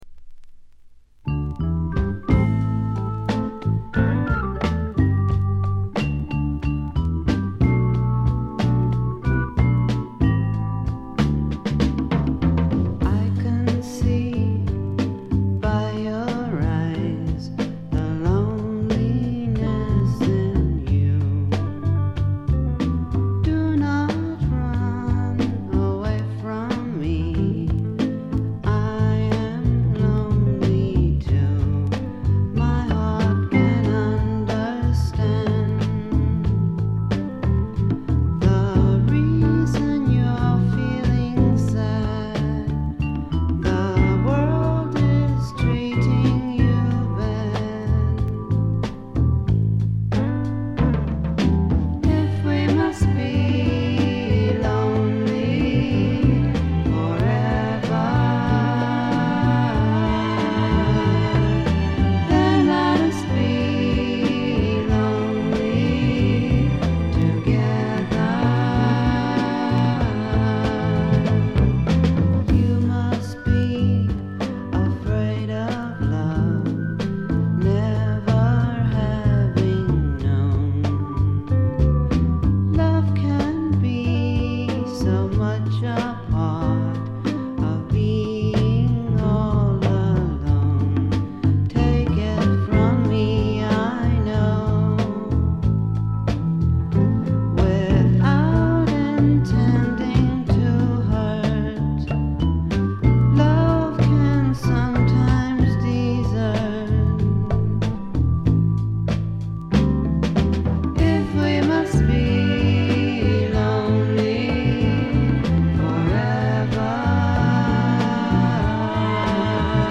軽微なバックグラウンドノイズ、チリプチ程度。
ソフト・ロック、ソフト・サイケ、ドリーミ・サイケといったあたりの言わずと知れた名盤です。
試聴曲は現品からの取り込み音源です。